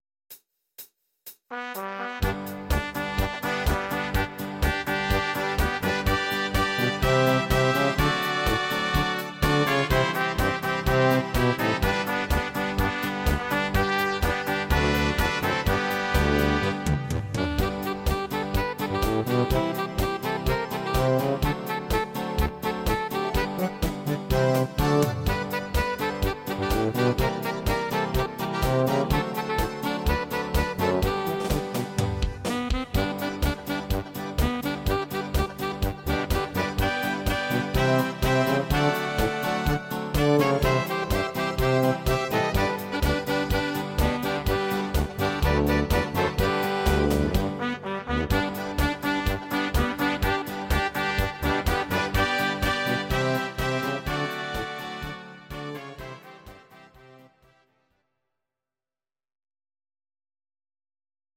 Audio Recordings based on Midi-files
German, Traditional/Folk, Volkstï¿½mlich